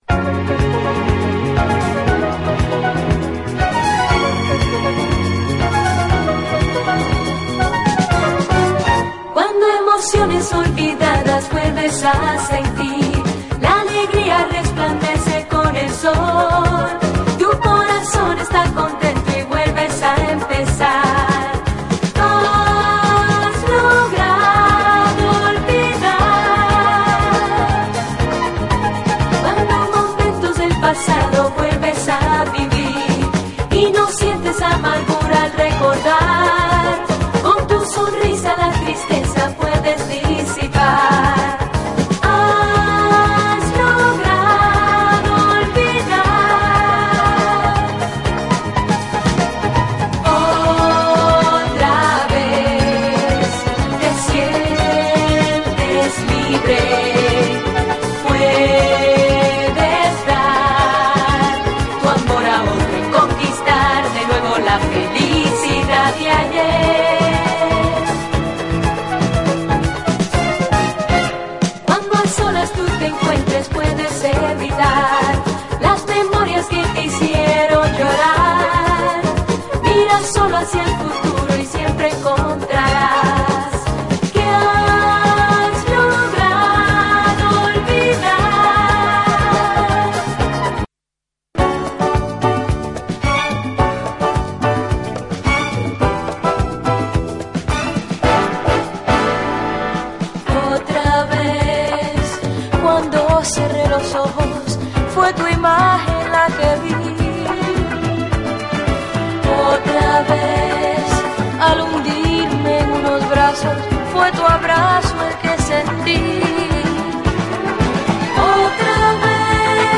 SOUL, 70's～ SOUL, DISCO, LATIN
トロピカル＆ファンタスティック！
キュート＆ロマンティック！